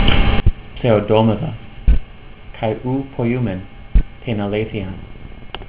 You can click on the verse to hear me read it.